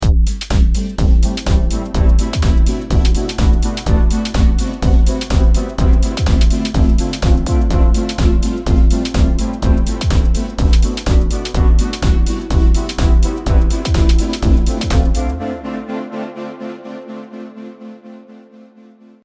今回、大きな流れのリバースではなく細かくタームでリバースをかけて、それを強調してみることにしました。エフェクト音のみの状態を聴いてみてください。
スタッターのような雰囲気に聞こえますが、細かくても一つ一つの音はリバースされています。